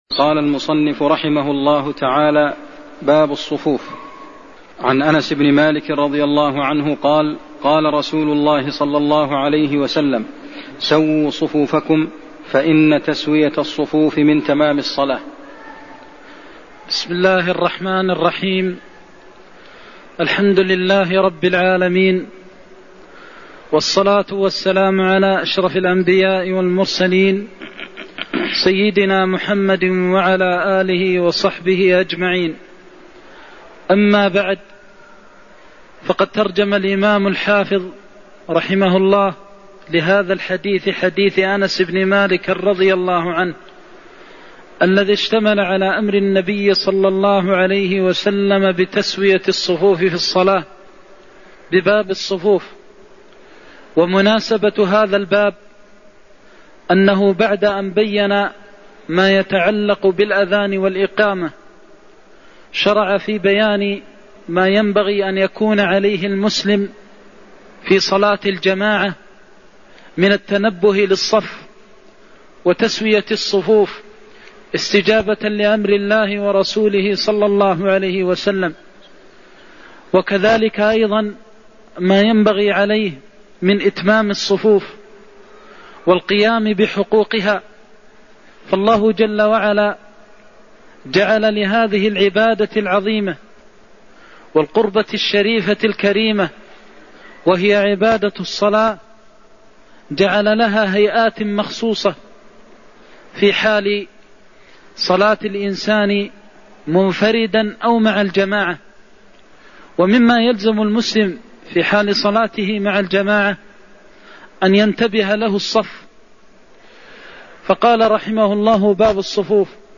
المكان: المسجد النبوي الشيخ: فضيلة الشيخ د. محمد بن محمد المختار فضيلة الشيخ د. محمد بن محمد المختار سووا صفوفكم فإن تسوية الصفوف من تمام الصلاة (67)